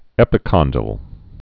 (ĕpĭ-kŏndĭl, -dl)